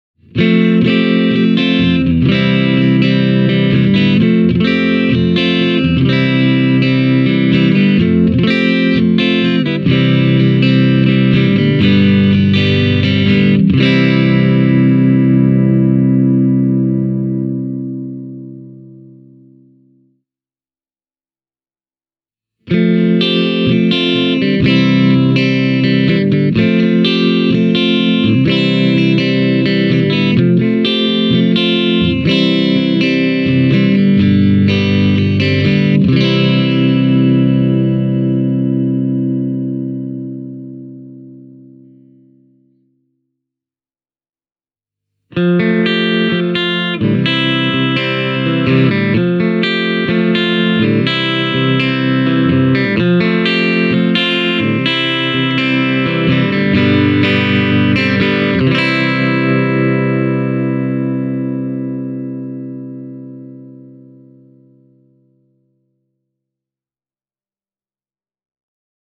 In my opinion, P-90s are the best choice for a guitar of this type, because their singlecoil construction makes for a more percussive and dynamic tone than what you’d get from a creamy humbucker.
Played into a clean amp channel you’ll get a nice, clucky attack and a open, yet strong overall voice, that will fit Jazz just as well as Brit Pop, Country, Blues or early Rock’n’Roll (why not add a Bigsby, perhaps?):
italia-fiorano-standard-e28093-clean.mp3